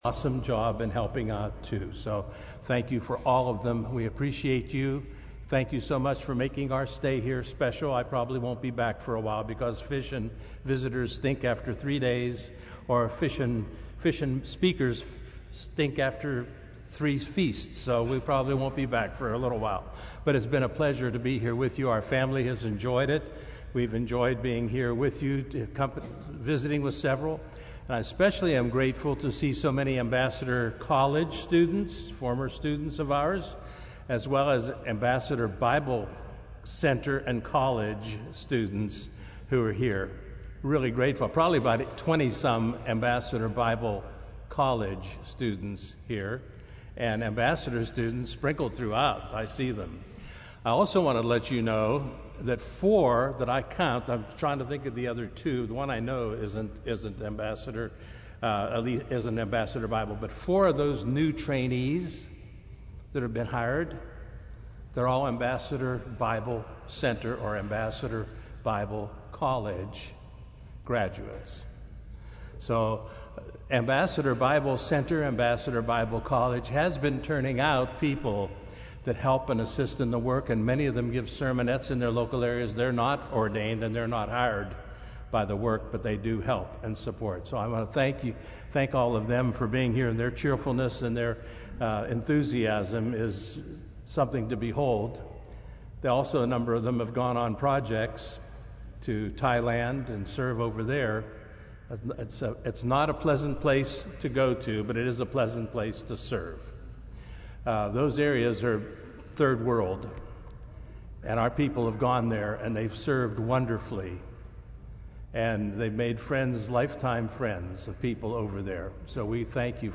This sermon was given at the Oceanside, California 2015 Feast site.